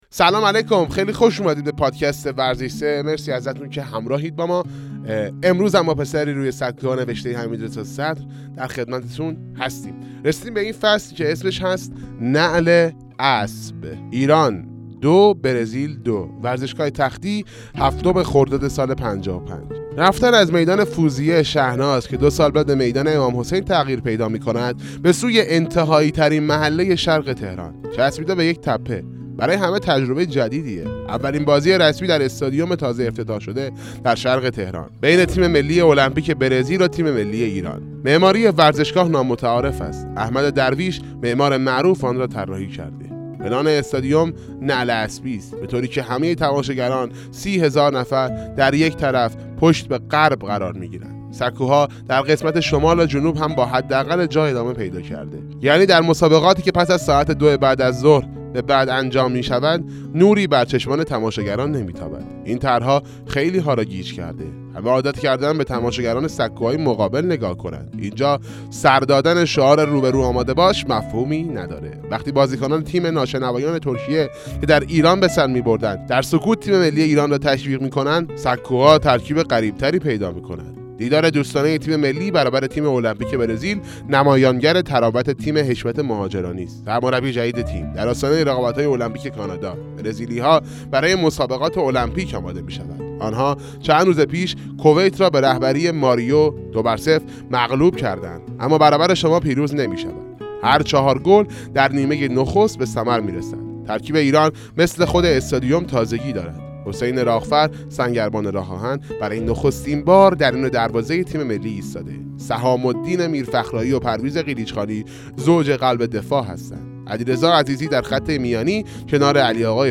7 دانلود اشتراک گذاری 0 511 پادکست 11 خرداد 1404 ساعت 15:04 ویتامین سه کتاب صوتی پسری روی سکو ها (قسمت چهل و چهارم) کتاب پسری روی سکوها وقایع نگاری چهاردهه فوتبال ایران است که در قالب رمان روایت شده است.